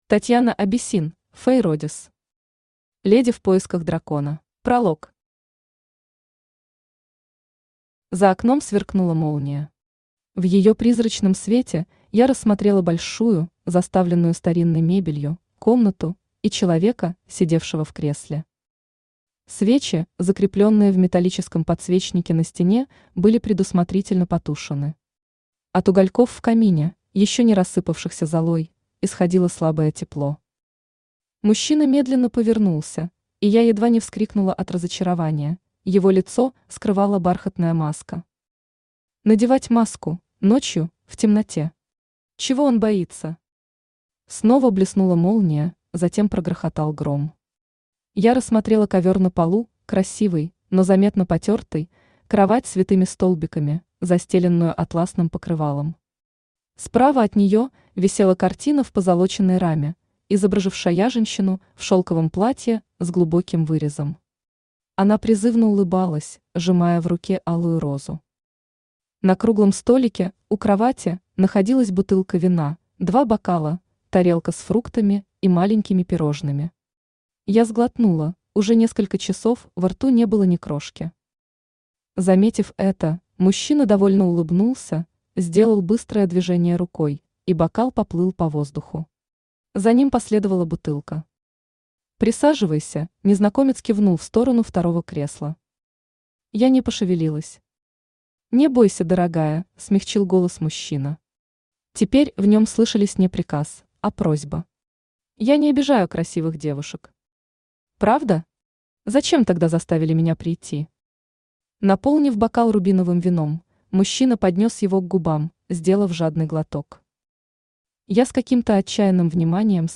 Aудиокнига Леди в поисках дракона Автор Татьяна Абиссин Читает аудиокнигу Авточтец ЛитРес.